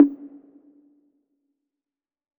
6CONGA LW.wav